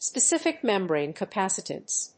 specific+membrane+capacitance.mp3